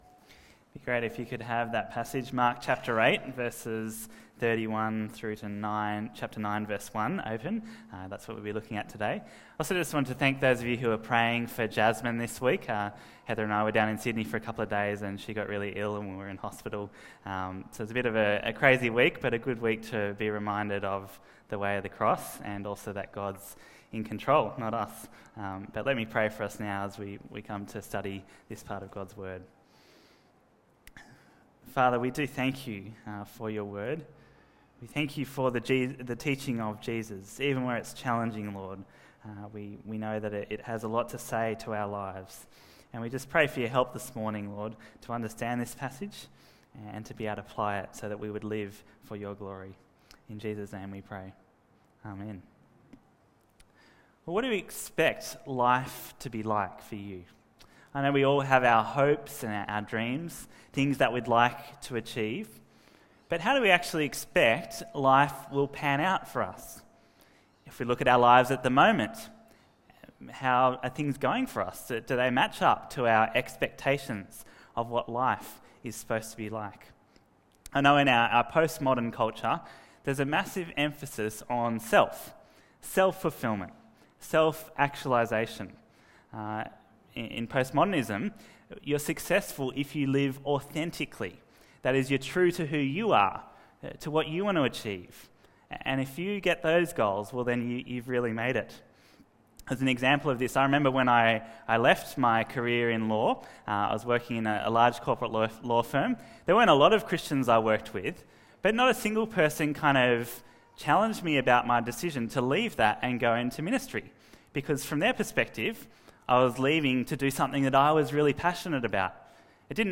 Bible Talks Bible Reading: Mark 8:31-9:1